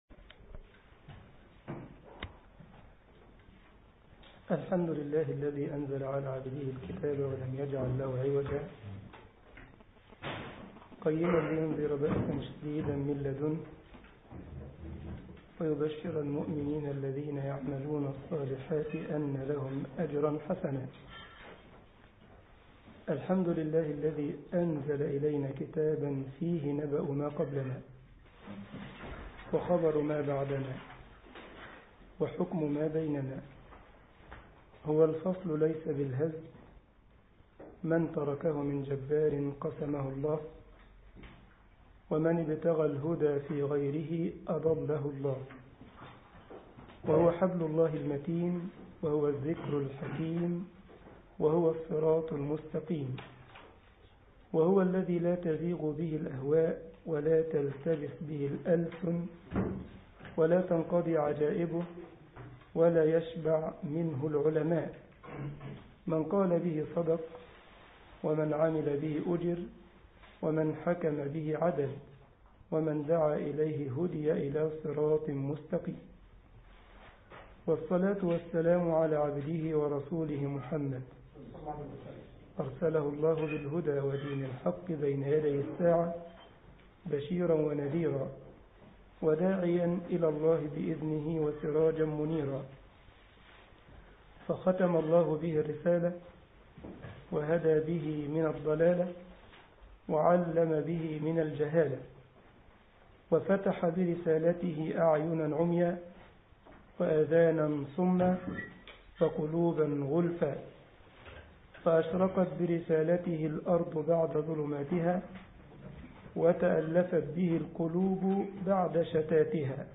الجمعية الإسلامية بالسارلند ـ ألمانيا درس